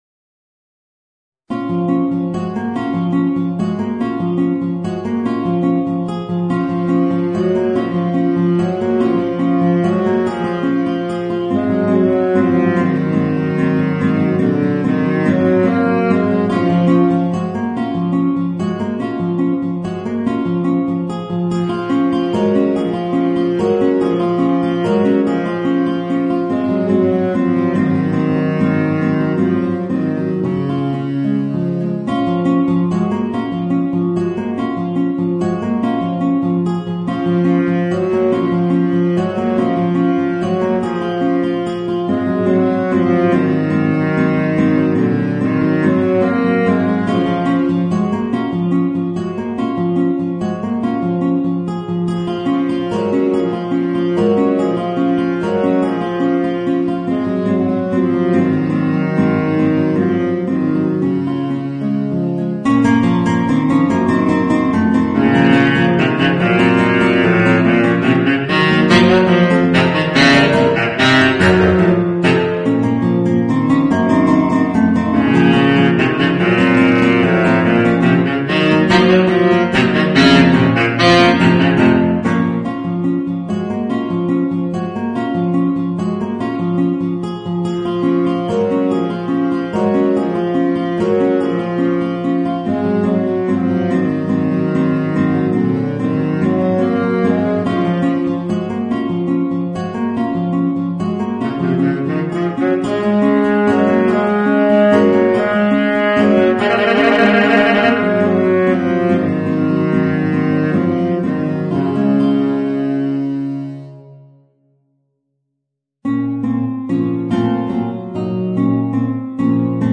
Saxophone baryton & guitare